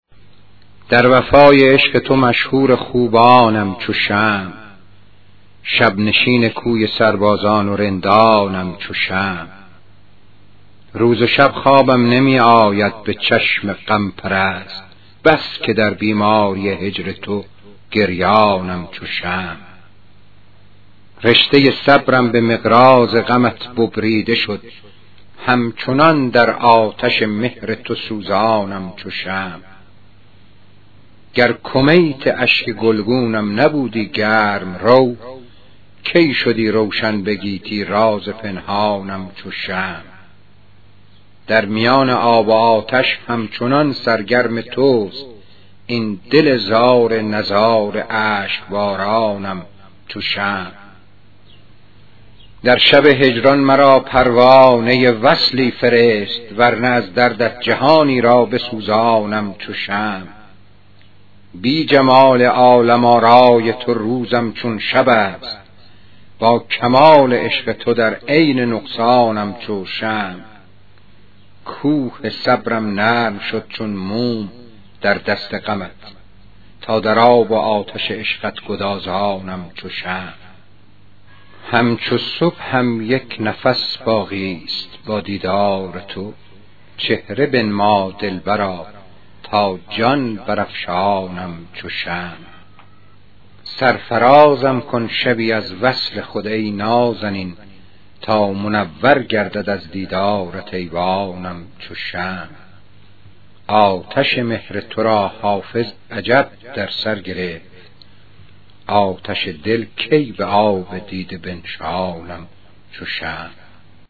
پخش صوتی غزل